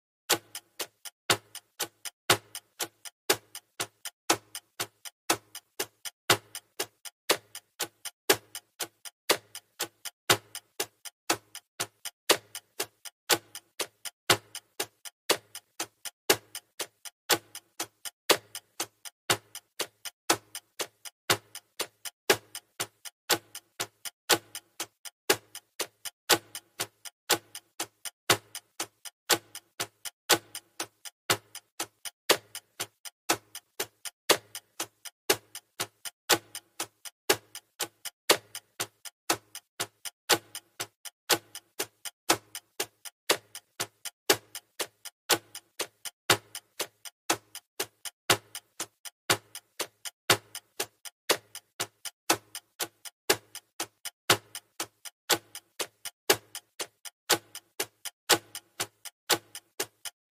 【環境音】カウントダウンタイマー1分）｜ Countdown Timer 1min【作業用・睡眠用BGM Sound Effects Free Download